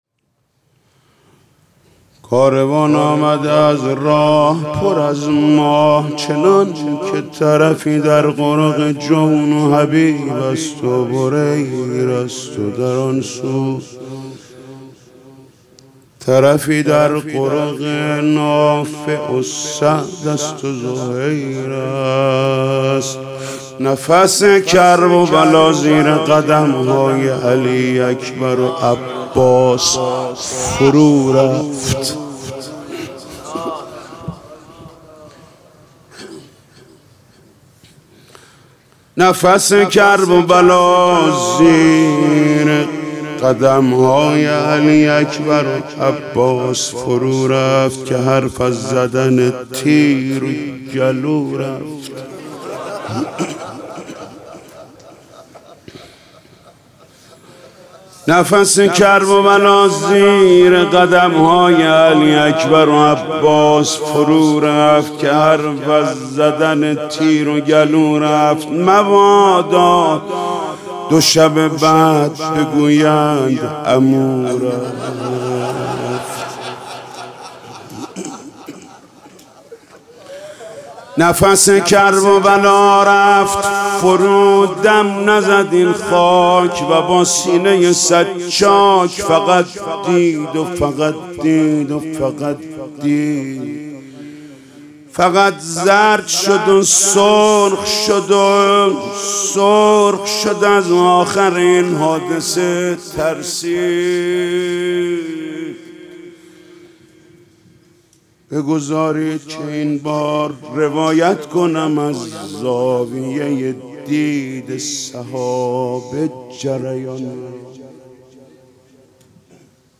برگزاری مراسم محرم حسینی 1401